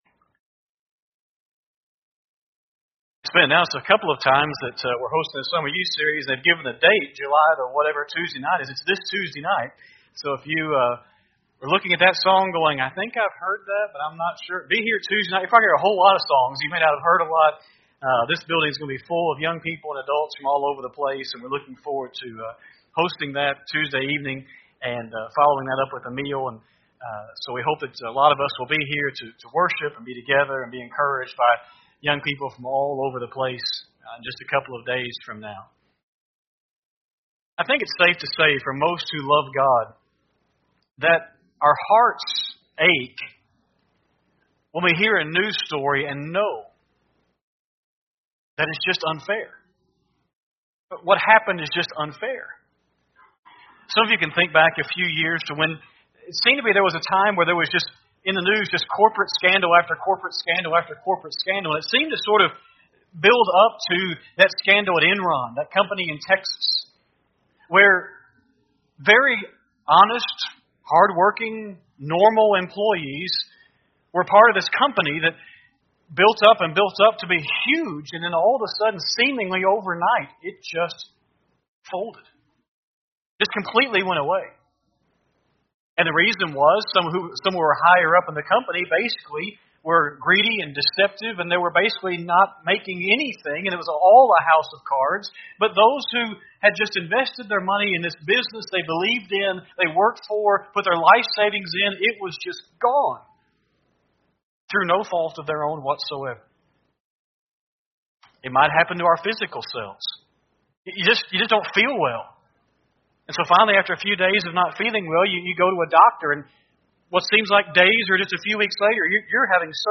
Sunday PM Sermon
7-14-24-Sunday-PM-Sermon.mp3